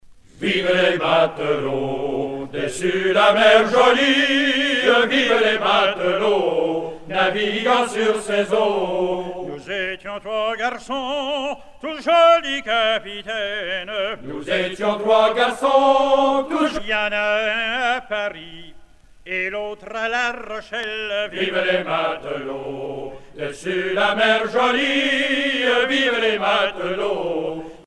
chanteur(s), chant, chanson, chansonnette
Genre laisse
Pièce musicale éditée